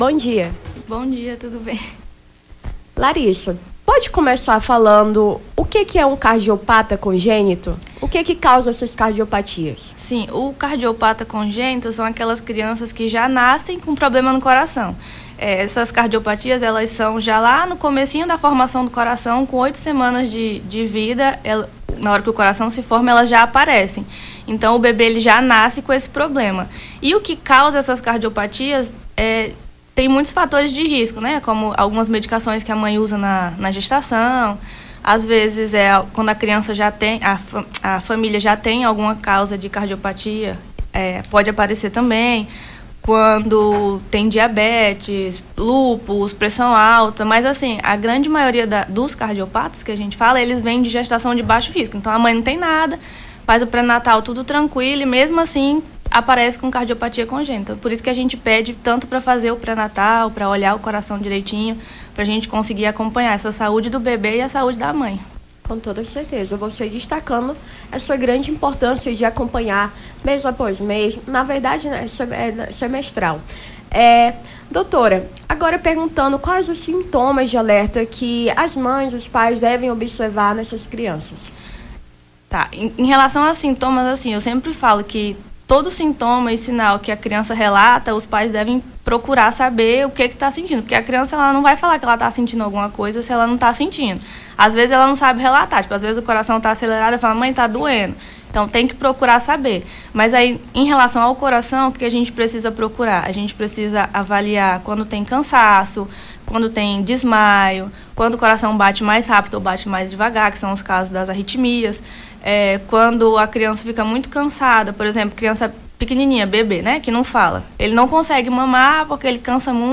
Nome do Artista - CENSURA - ENTREVISTA (BOM DIA DOUTOR) 17-08-23.mp3